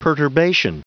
Prononciation du mot perturbation en anglais (fichier audio)
Prononciation du mot : perturbation